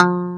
CLV_ClavDBF_4 3a.wav